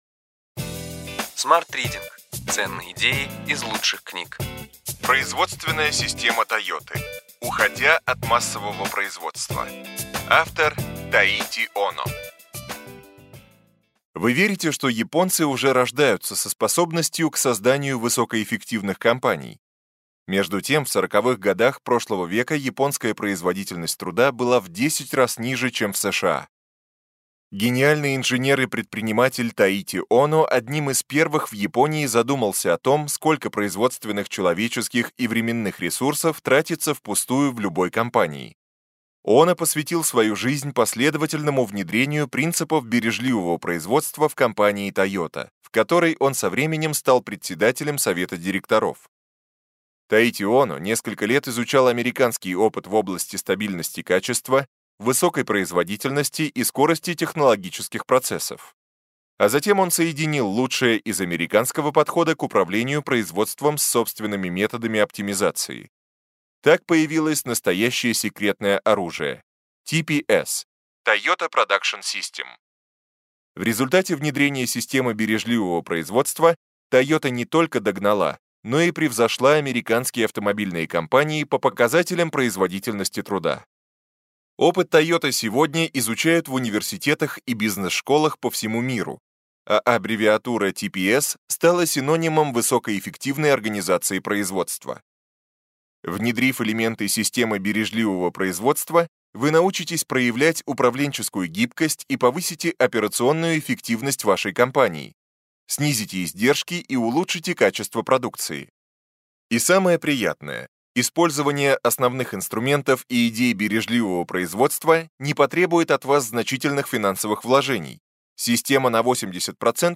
Аудиокнига Ключевые идеи книги: Производственная система «Тойоты». Уходя от массового производства.